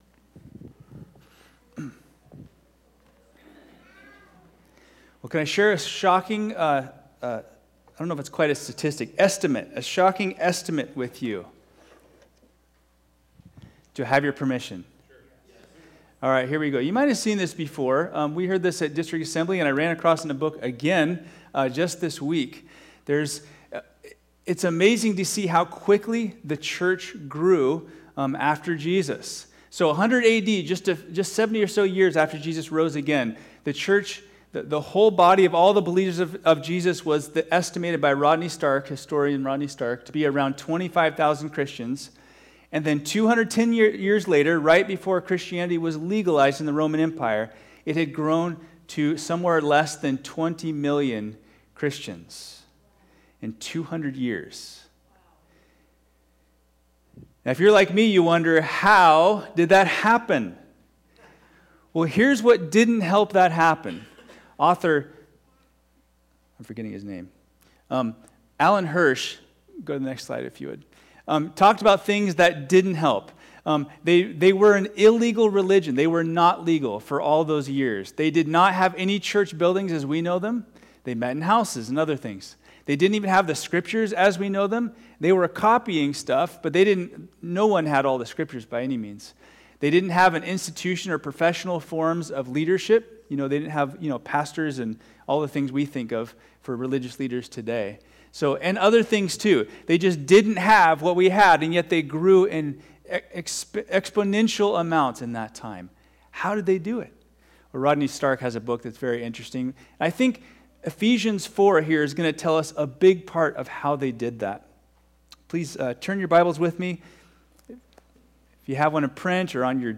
All Sermons One God